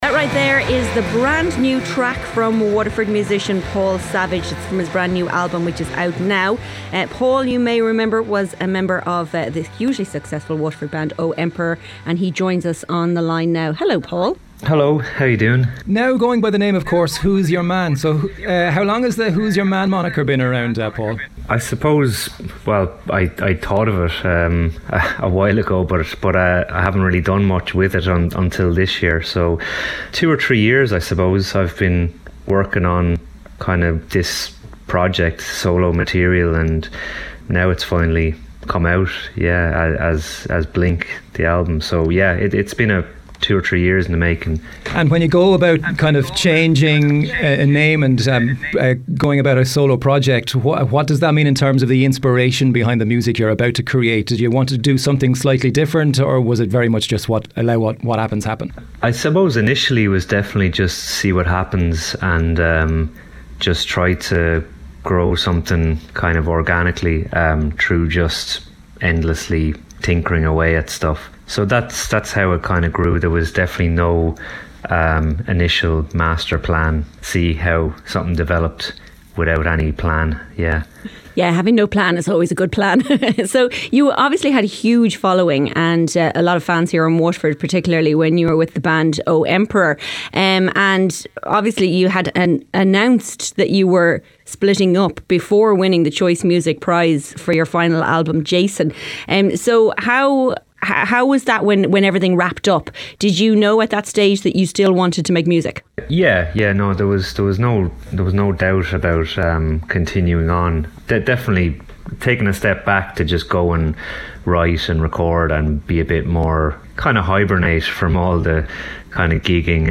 As part of the offering we had a number of guests in studio, and here’s your chance to hear them back: